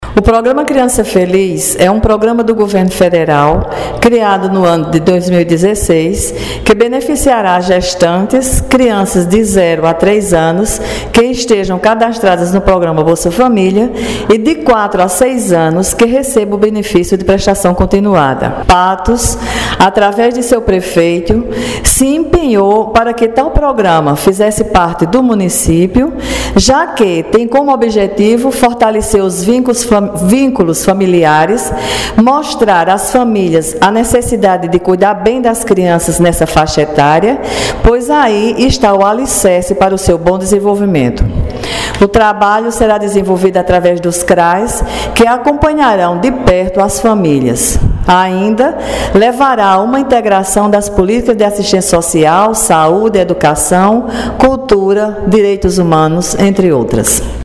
Secretária de Desenvolvimento Social, Maria da Guia Lustosa, fala sobre o programa Criança Feliz –